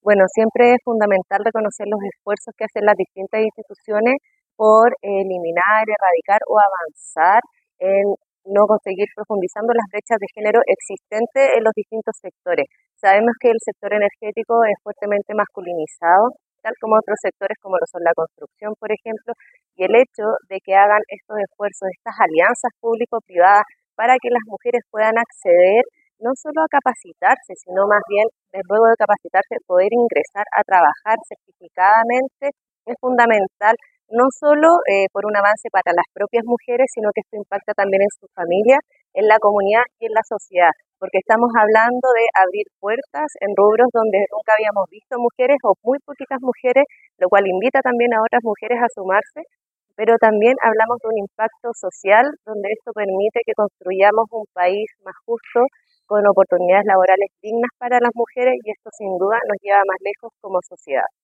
El Museo Interactivo de Osorno fue el lugar de encuentro para desarrollar la Segunda Mesa Regional de Energía Más Mujeres y Capital Humano, una iniciativa desarrollada en conjunto entre el Ministerio de Energía y la Agencia de Sostenibilidad Energética.